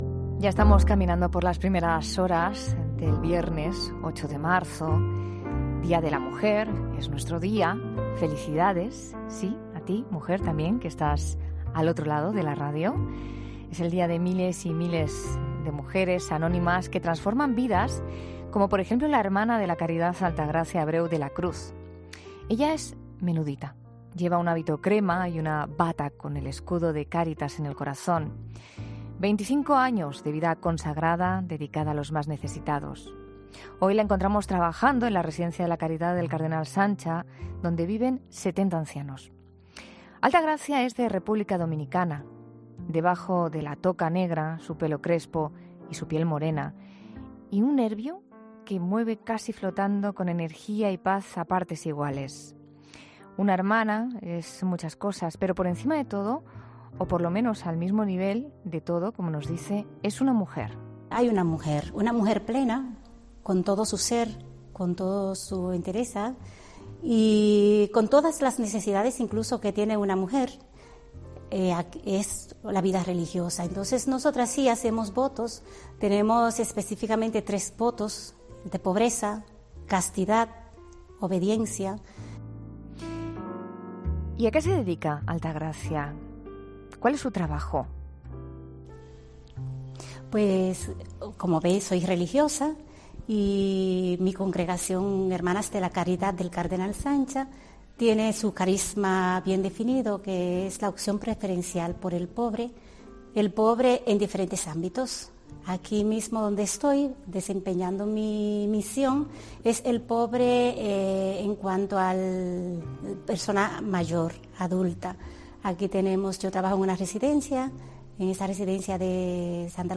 Espido Freire, que ha presentado en 'La Noche de COPE', su nuevo libro 'Pioneras: Mujeres que abrieron camino'.